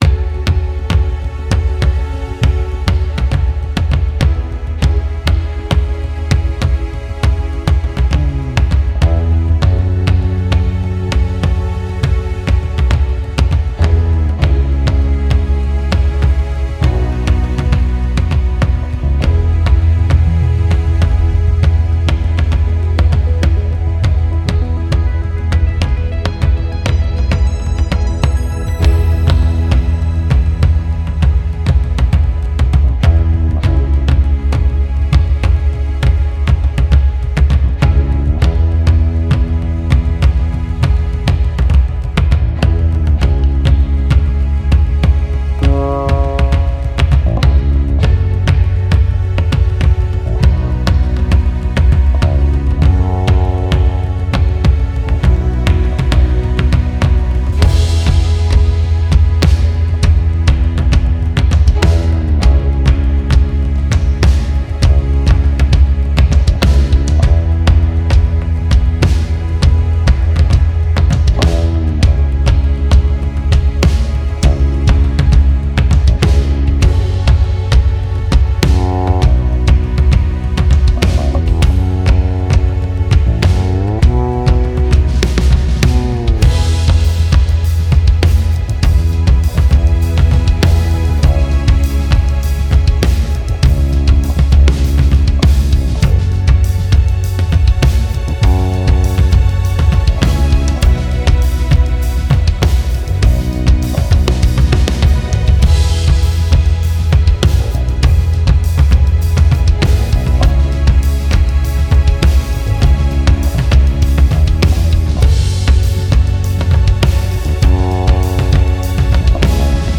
Performance Track
w/o Background Vocals